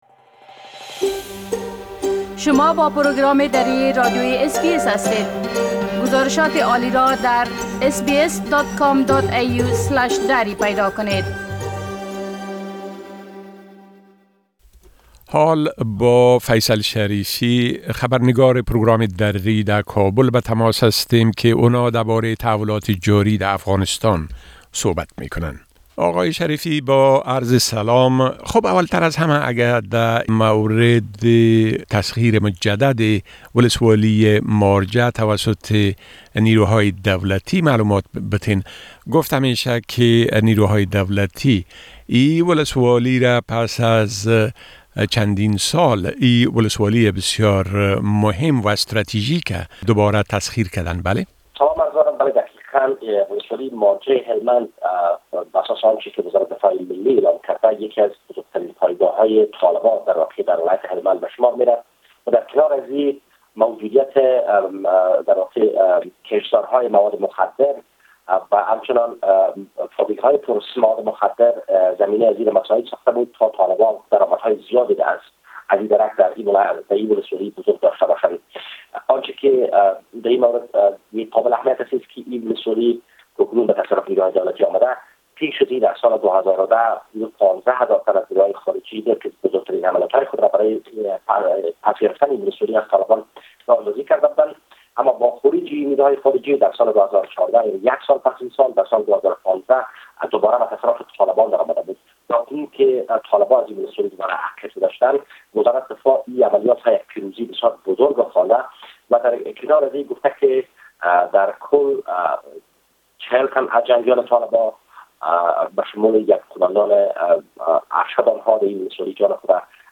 گرازش كامل خبرنگار ما در كابل دربارۀ تسليمى شمار بيشتر داعيشى ها٬ مسايل مربوط به انتخابات رياست جمهورى٬ و رويداد هاى مهم ديگر در افغانستان را در اينجا شنيده ميتوانيد.